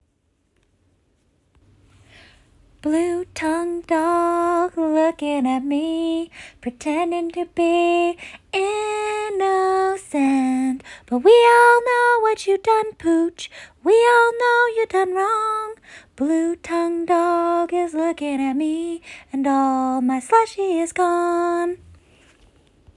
Blue tongued dog (nonsense song